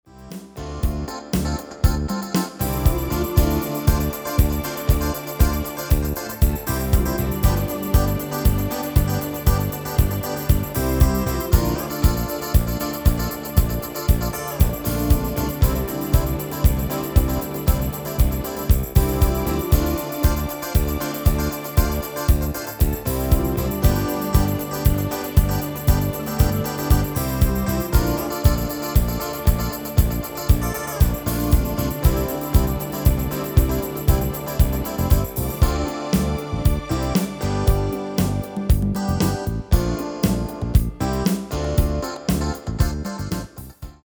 GM-Only MIDI File Euro 8.50
Demo's zijn eigen opnames van onze digitale arrangementen.